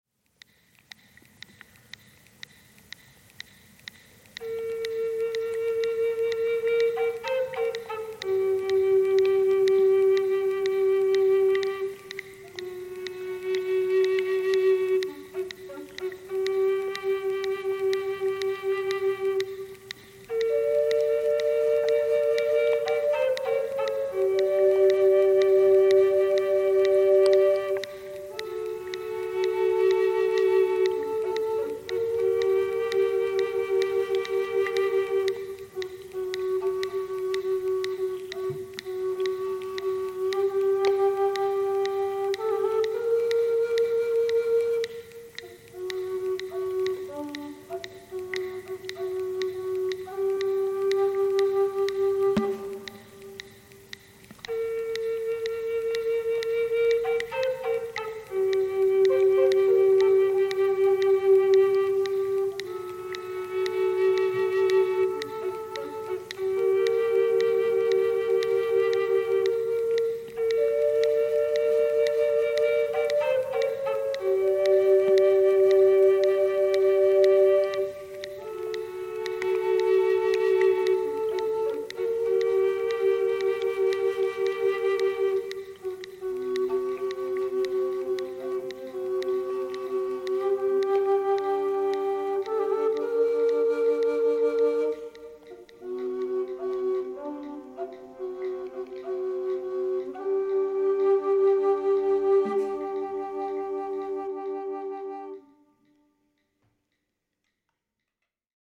To reflect this in my piece, I have kept the sound of the hydrophone from the original recording as a time clock. "Accompanying, I have played layers of tenor recorder to represent the harmony of breath beneath th…